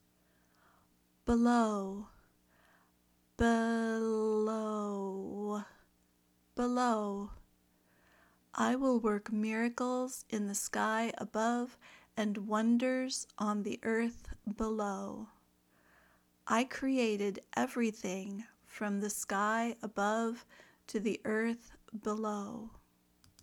/bɪˈʊ/ (adverb)